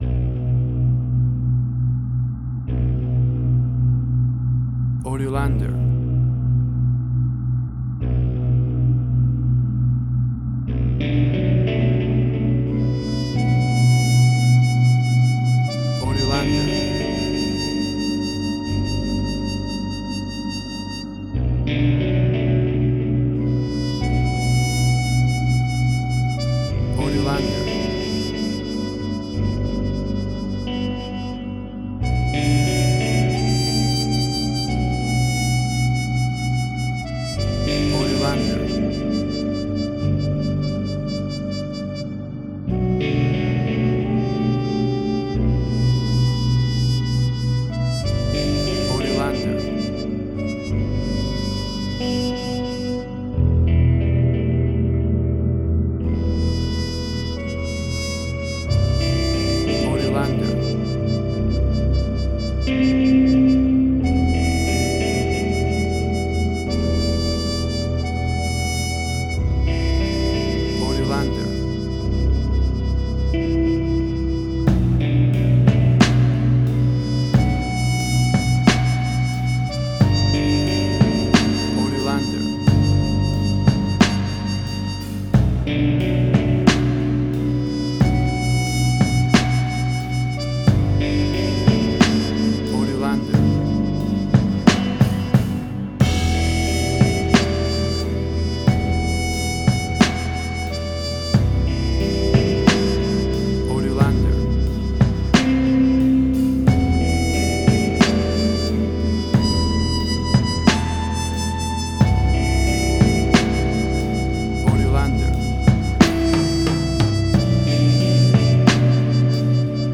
Modern Film Noir.
Tempo (BPM): 45